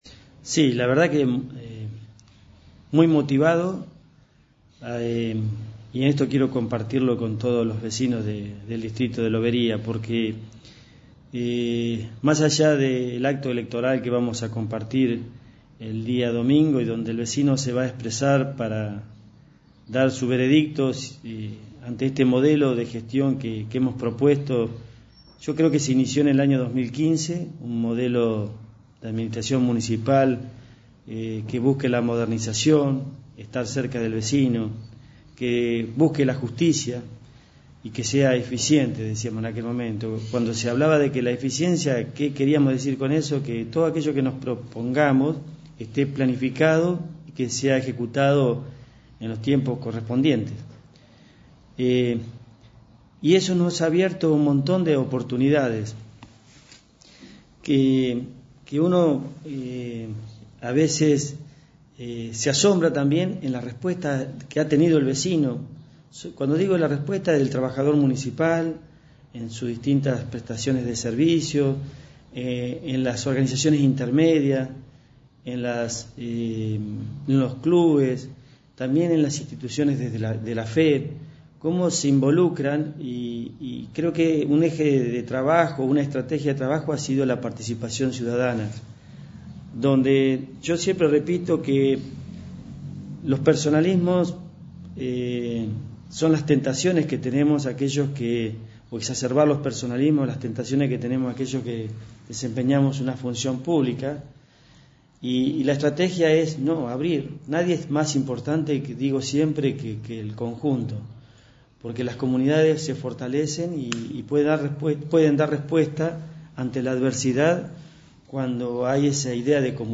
El Intendente Juan José Fioramonti dialogó con 2261 en la previa a las elecciones que este domingo 14 de noviembre permitirán a los loberenses elegir Concejales y Consejeros Escolares. En un repaso por los distintos puntos de la gestión que encabeza, consideró que el acompañamiento de los votantes a la lista de Juntos será esencial para mantener el rumbo del modelo de gestión municipal.